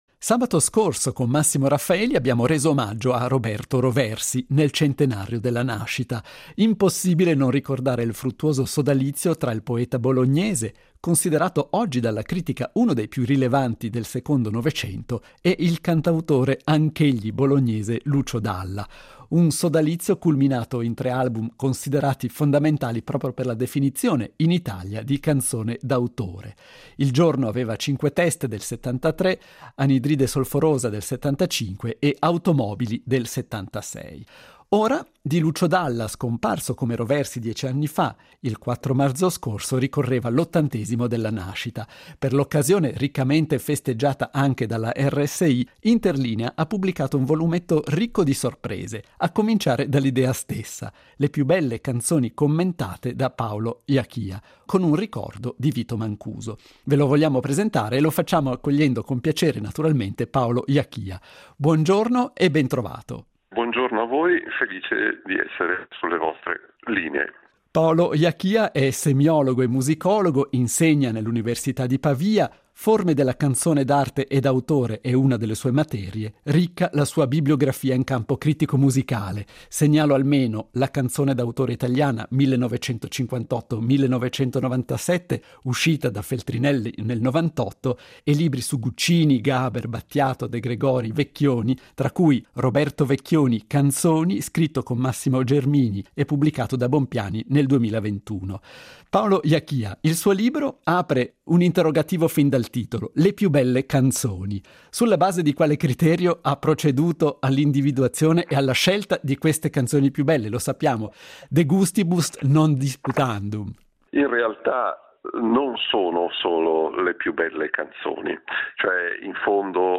Intervista integrale.